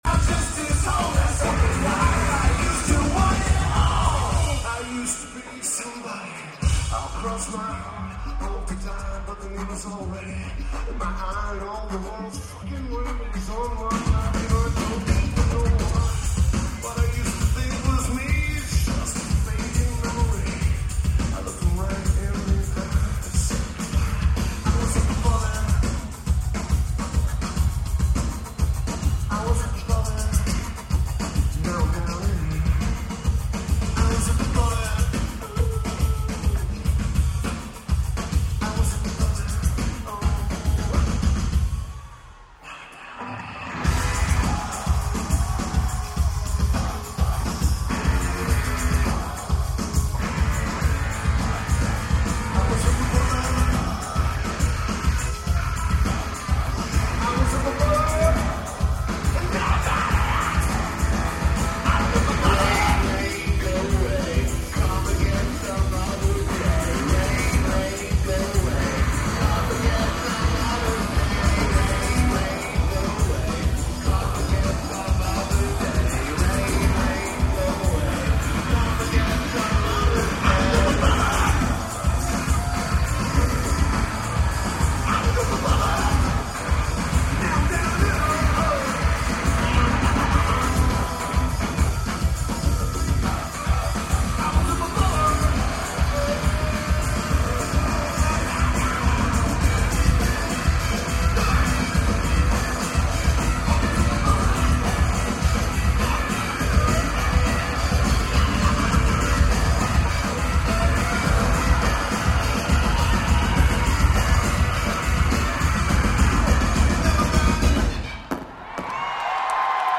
DTE Energy Music Theatre
Lineage: Audio - AUD (DPA 4061s + SP-SPSB-1 + iRiver H120)